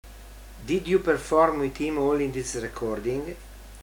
Una intervista